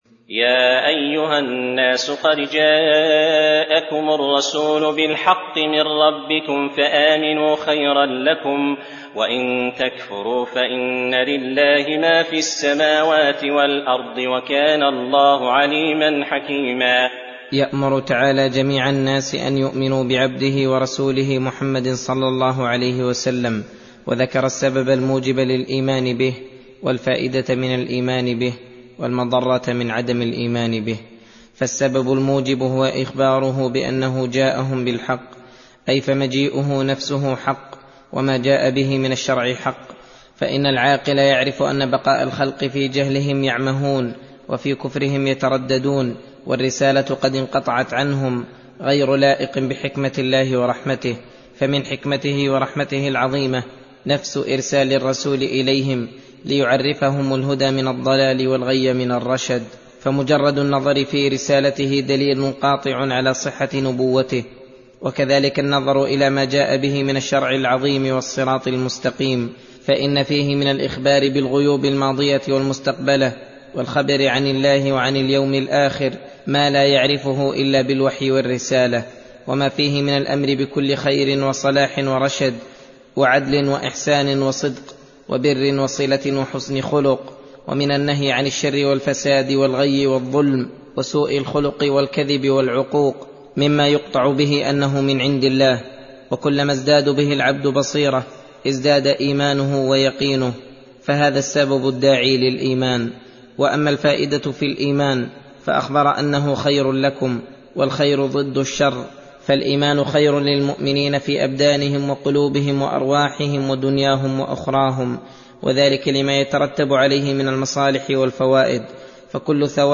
درس (15) : تفسير سورة النساء: (170-176)